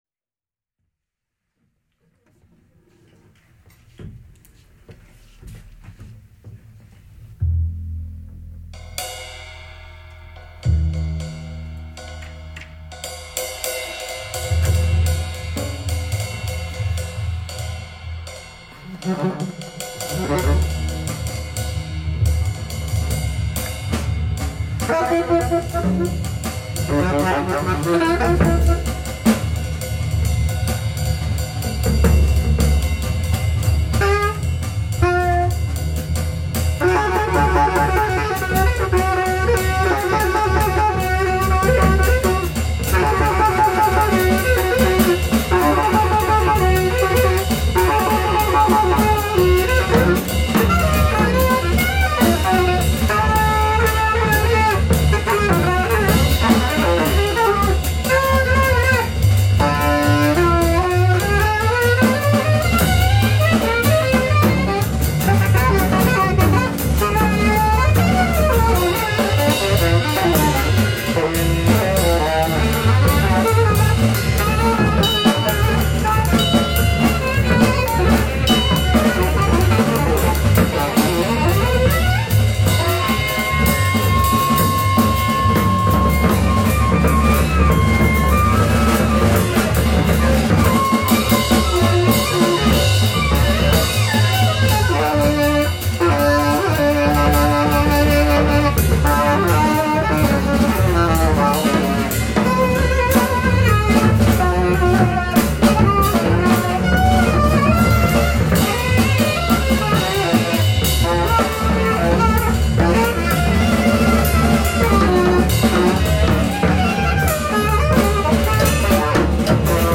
soprano & alto sax
double bass
drums
He phrases almost like a horn.
facettenreichen Sturm-und-Drang-Jazz